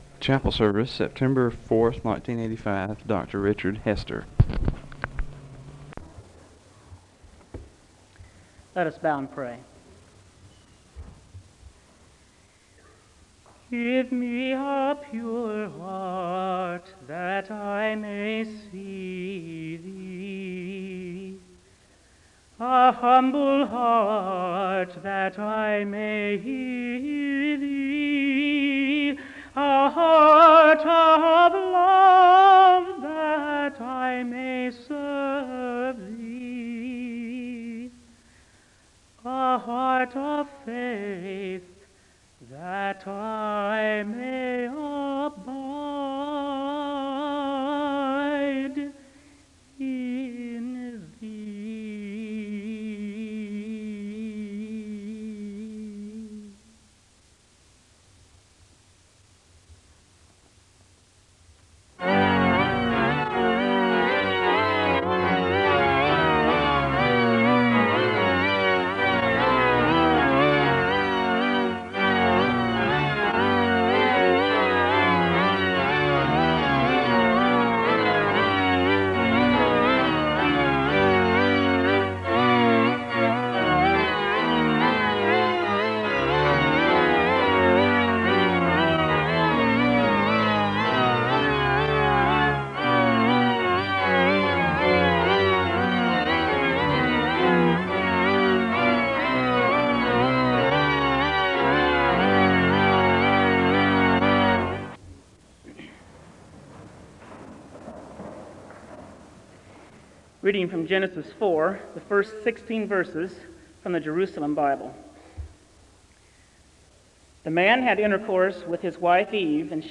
The service begins with a prayer (0:00-1:02). There is organ music (1:03-1:46). There is a Scripture reading from Genesis 4:1-6 (1:47-4:20). There is a song of worship (4:21-7:48).